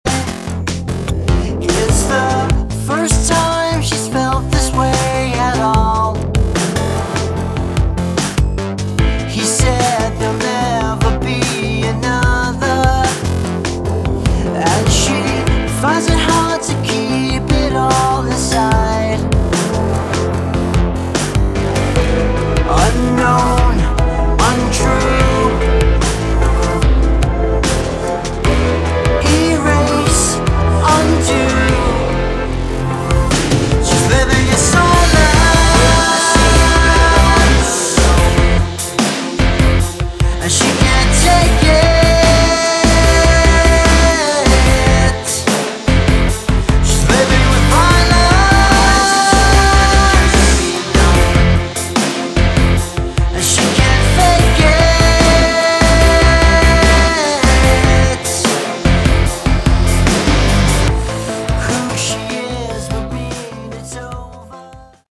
Category: Progressive Melodic Rock
vocals, drums, keys
keys, programming, sound design
bass guitar
electric and acoustic guitars